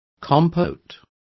Complete with pronunciation of the translation of compotes.